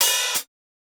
TIGHT_OPEN HH.wav